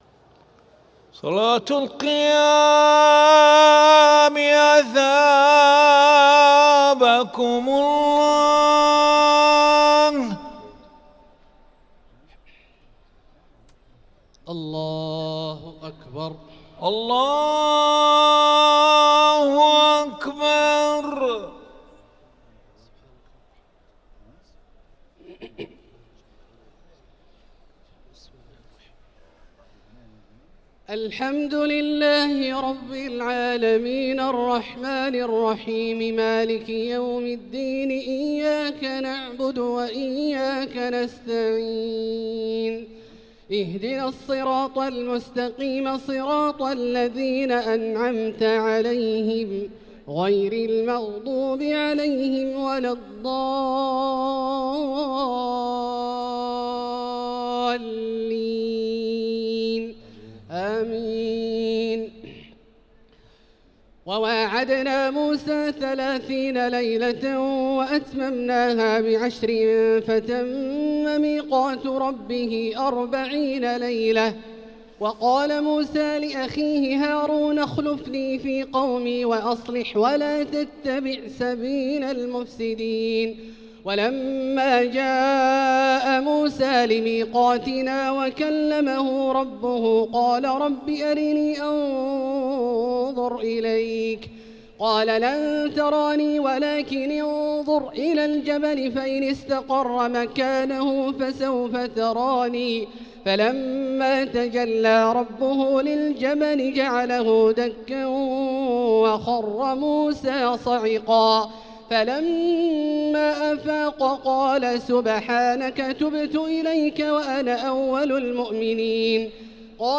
صلاة التراويح ليلة 12 رمضان 1445 للقارئ عبدالله البعيجان - الثلاث التسليمات الأولى صلاة التراويح